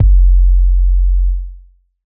Waka KICK Edited (49).wav